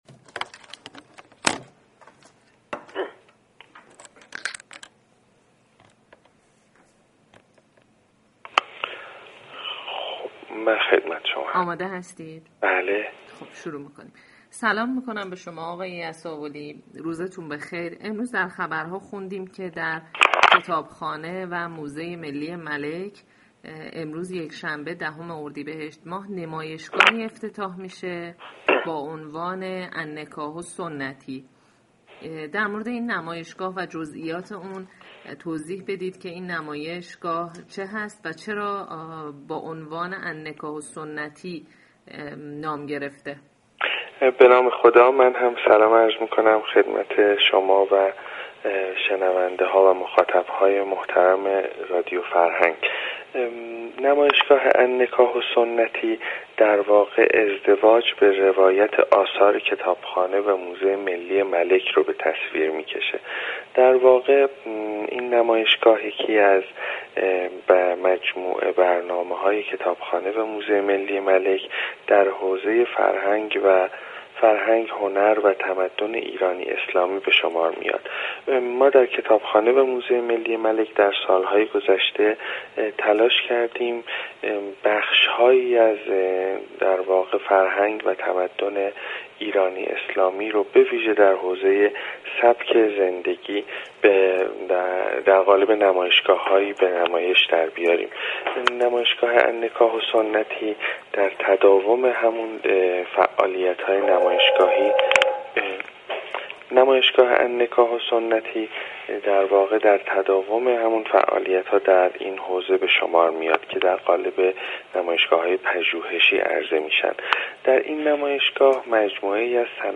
گفتگوی اختصاصی